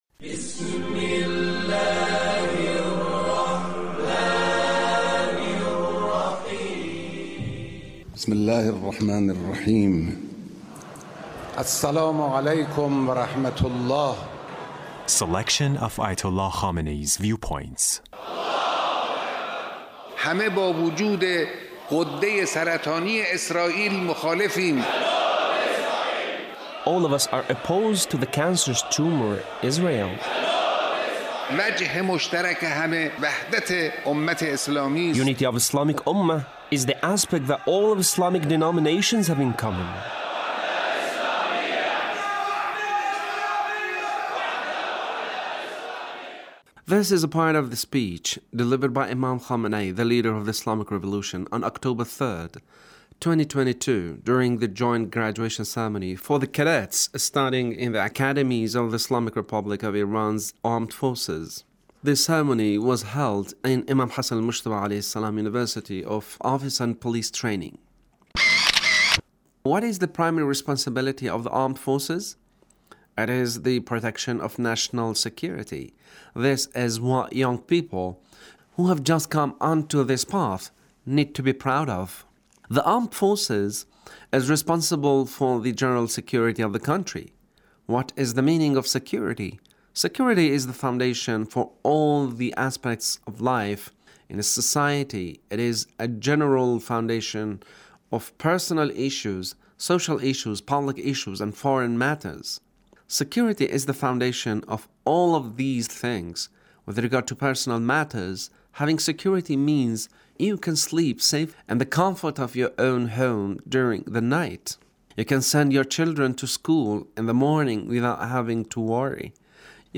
Leader's Speech on Graduation ceremony of Imam Hassan Mojtaba University